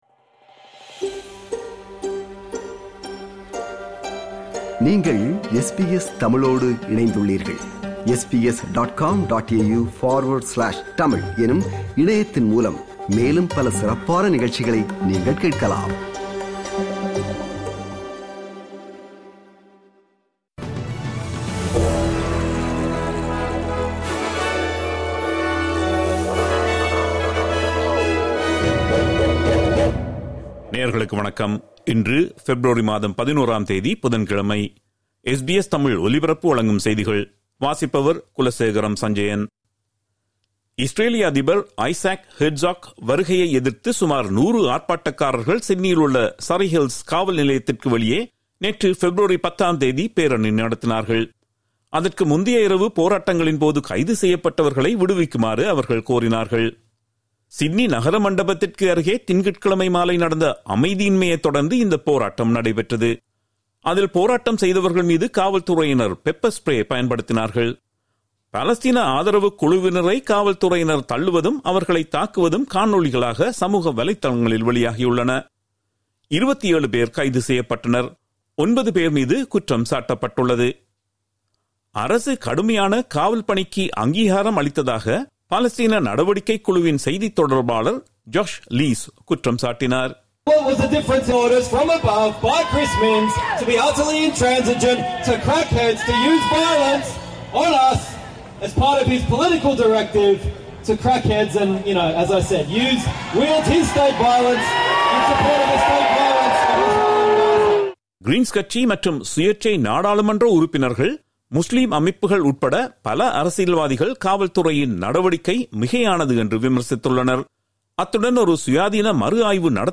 SBS தமிழ் ஒலிபரப்பின் இன்றைய (புதன்கிழமை 11/02/2026) செய்திகள்.